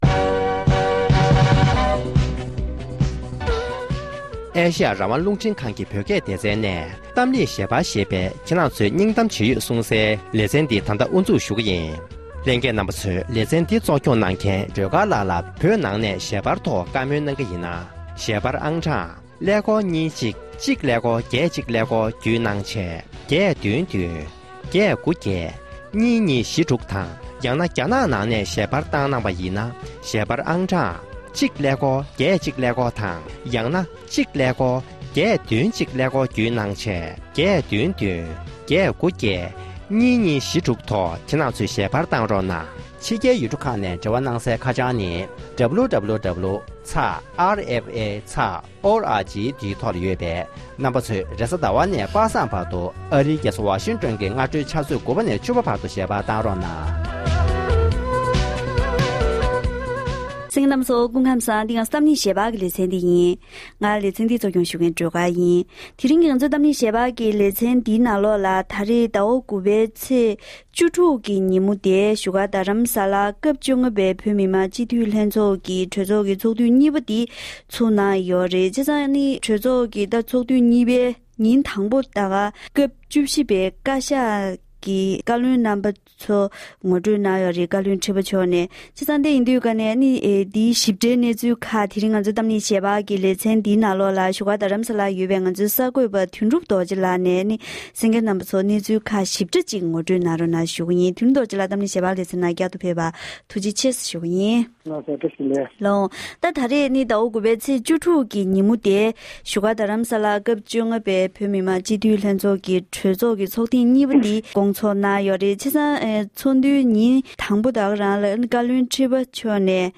༄༅༎དེ་རིང་གི་གཏམ་གླེང་ཞལ་པར་གྱི་ལེ་ཚན་ནང་དུ་སྐབས་༡༥པའི་བོད་མི་མང་སྤྱི་འཐུས་ལྷན་ཚོགས་ཀྱི་ཚོགས་དུས་གཉིས་པ་དབུ་བཛུགས་གནང་ཡོད་པའི་ཐོག་འཛིན་སྐྱོང་བཀའ་ཤག་གི་བཀའ་བློན་རྣམ་པ་ཐུགས་གཏན་འཁེལ་བའི་སྐོར་